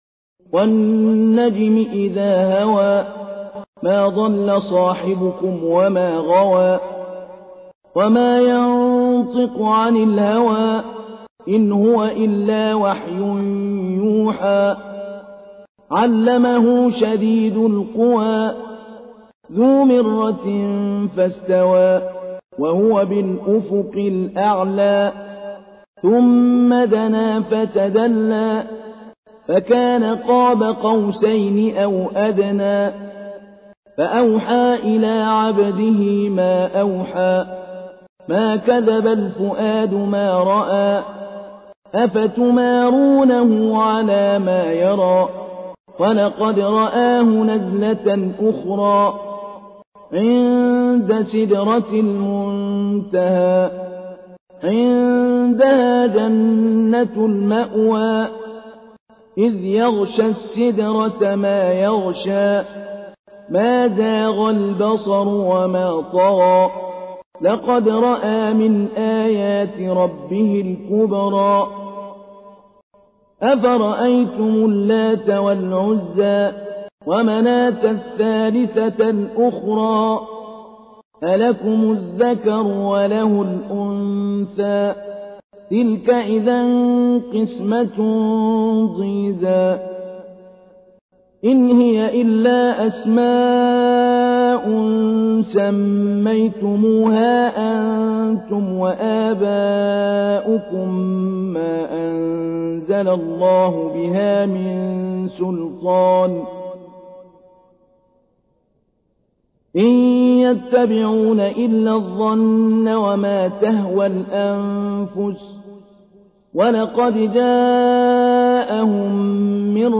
تحميل : 53. سورة النجم / القارئ محمود علي البنا / القرآن الكريم / موقع يا حسين